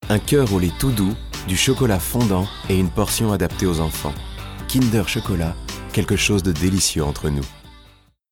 Bande demo son